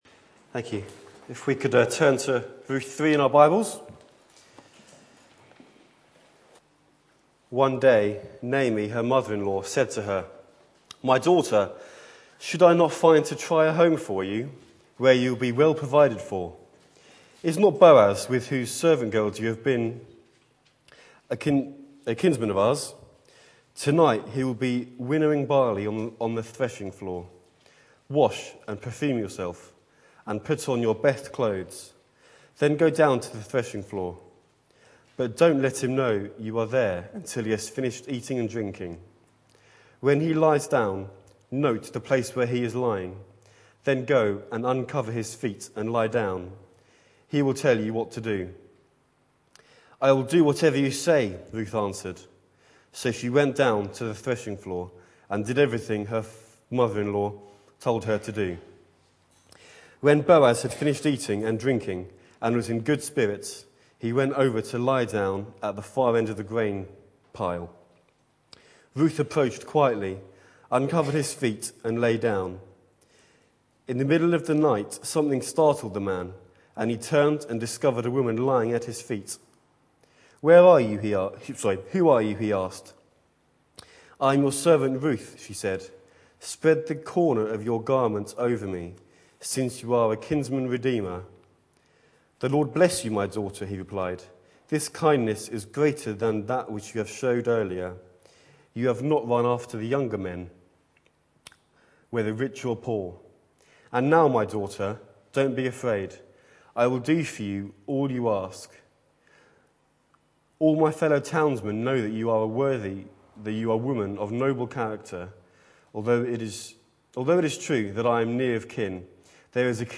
Back to Sermons Spread your garment over me